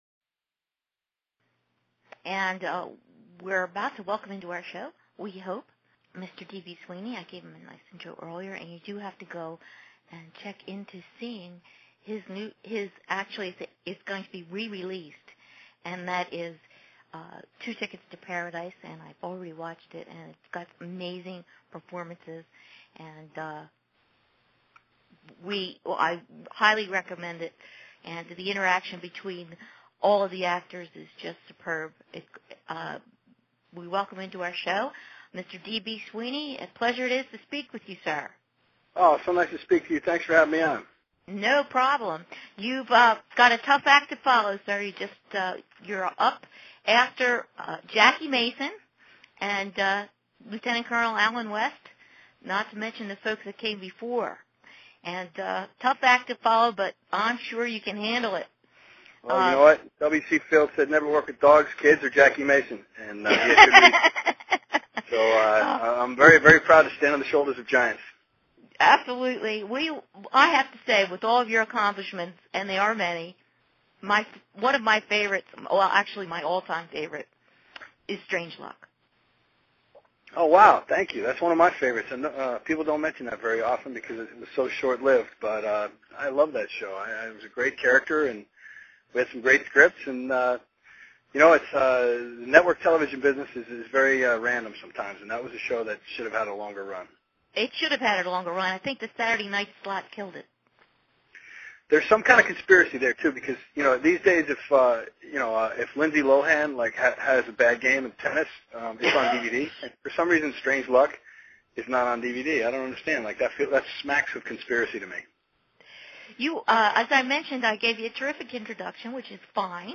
This interview can be heard in the Freedom Radio show in its entirety